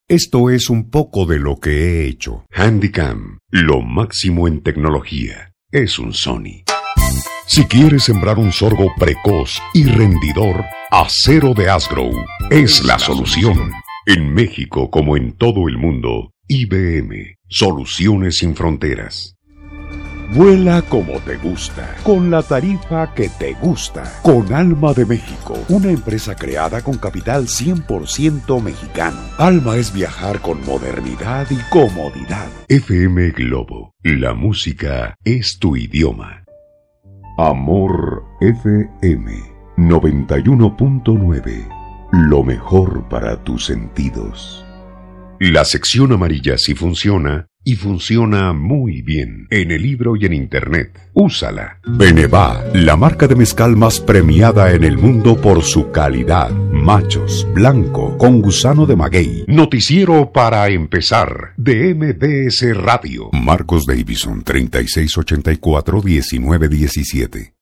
Sprecher mexikanisches spanisch LOCUTOR MEXICANO DE AMPLIA EXPERIENCIA
Sprechprobe: Werbung (Muttersprache):